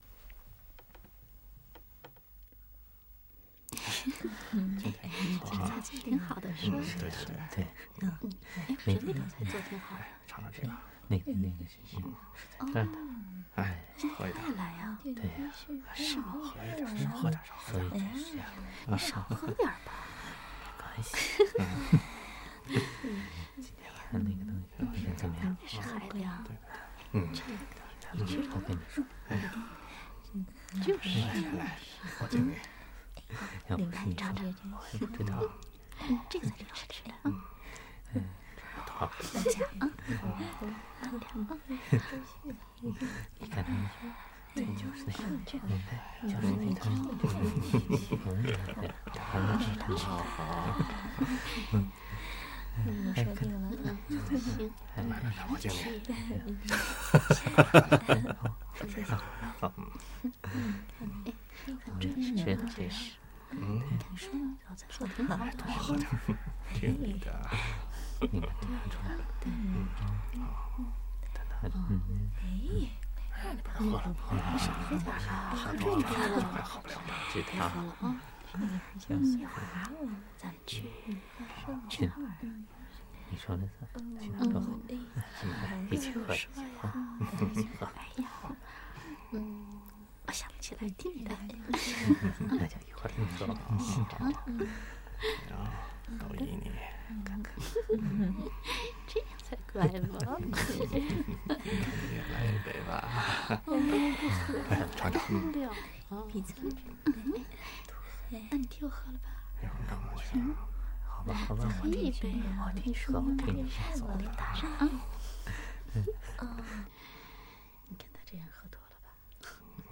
中国 " 中国苏州餐厅
描述：中国苏州的餐厅氛围。
Tag: R estaurant 亚洲 中国 苏州